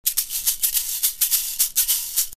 The LP Shake-It is a compact, durable, metal shaker that's great for a wide variety of musical situations. Shake it easy for lighter sounds or shake it harder to cut through live music with ease.
LP LP440 Sound Sample